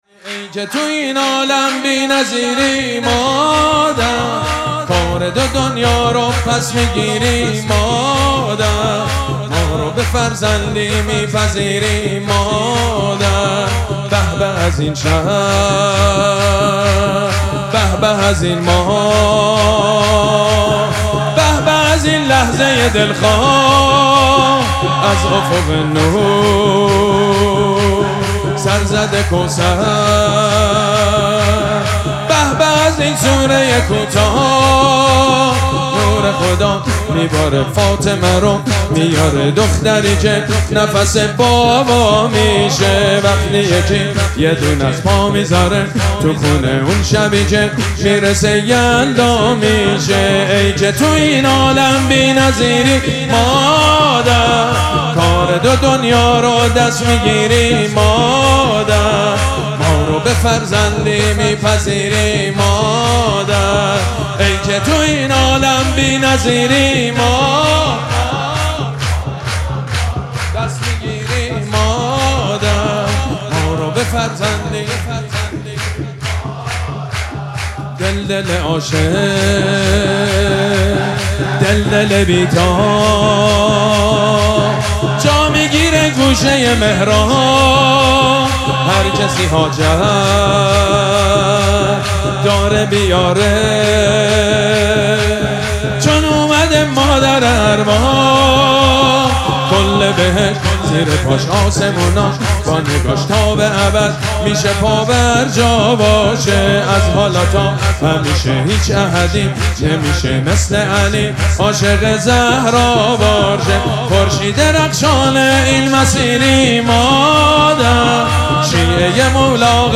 مراسم جشن ولادت حضرت زهرا سلام الله علیها
شور
مداح
حاج سید مجید بنی فاطمه